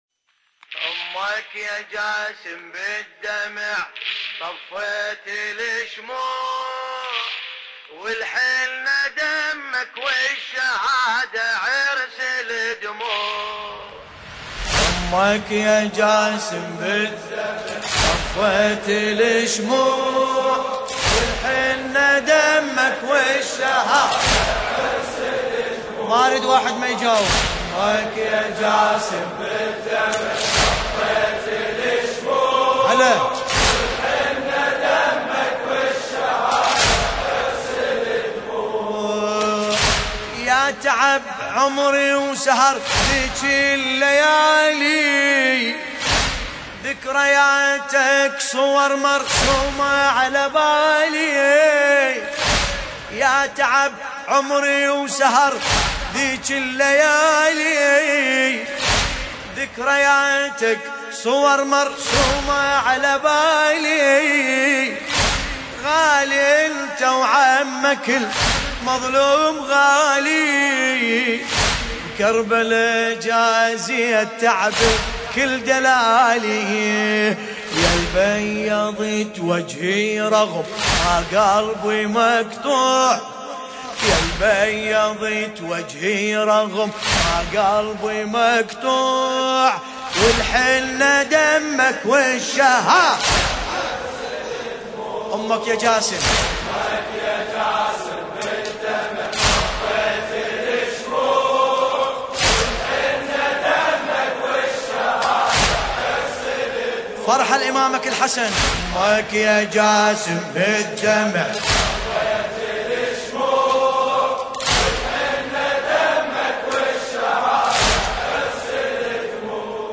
المناسبة : استشهاد القاسم بن الحسن (ع)
الزمن : ليلة 8 محرم 1440 هـ